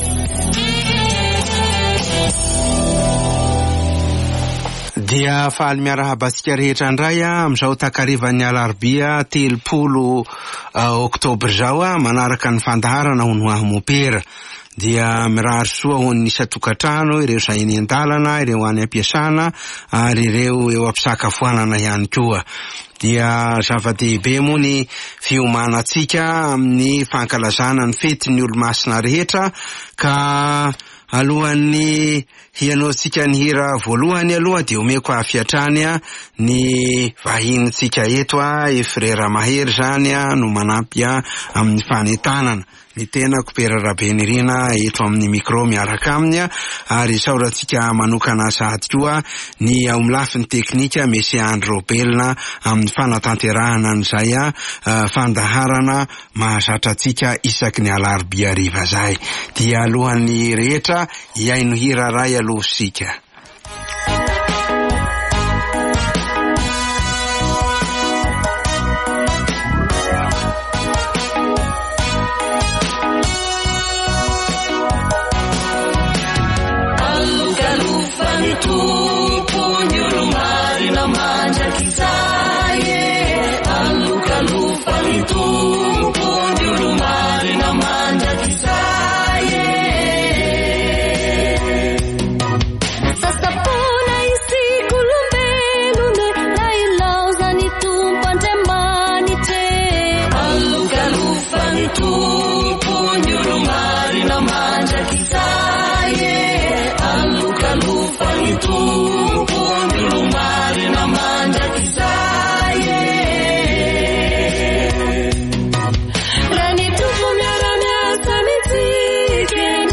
Emission "Instant avec un prêtre" sur Radio Don Bosco, tous les mercredis soir.